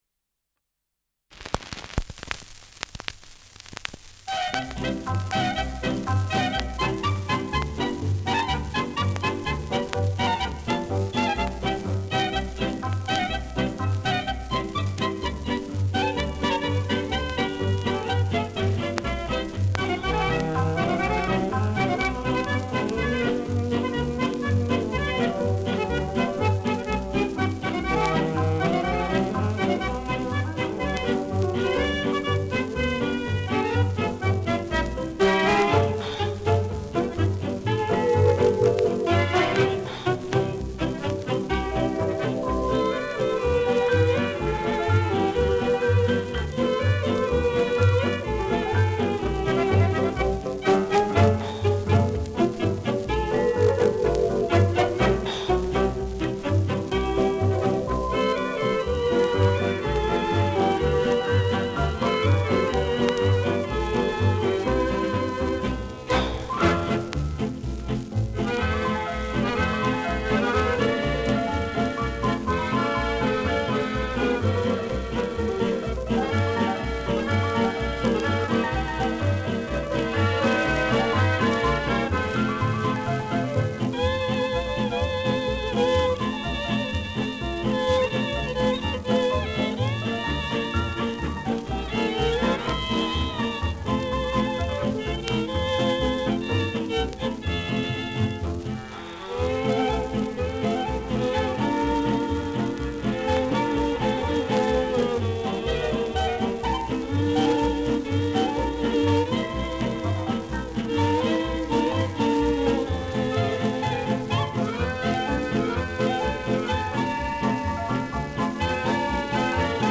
Schellackplatte
Leicht abgespielt : Gelegentlich leichtes Knacken